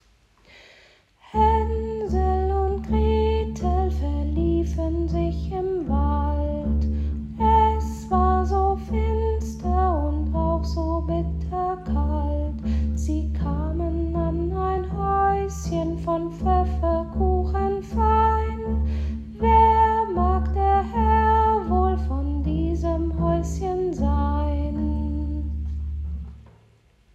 Beispiele Bass
Hänsel und Gretel (pizzicato - gezupft; Tempo: 83)
Haensel_und_Gretel___pizzicato___Tempo_83___Bass.mp3